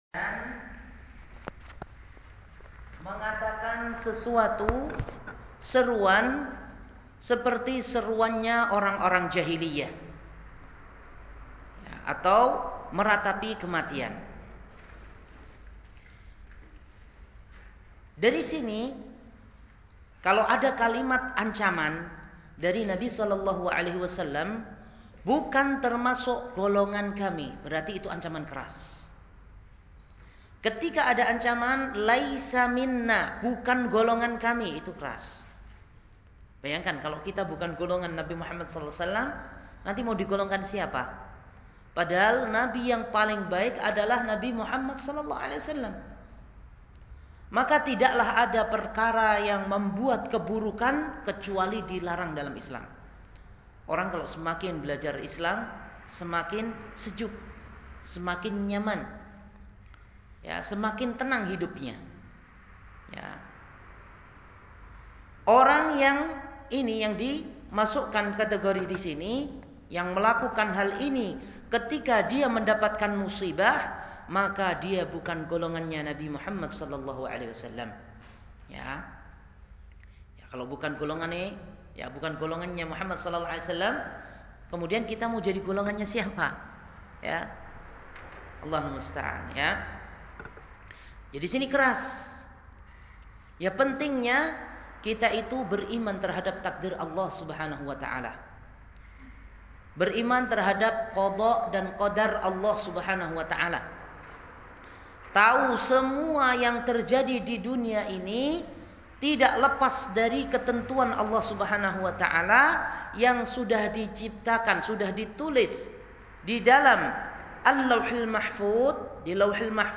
Kajian Sabtu – Barwa Village Barwa Village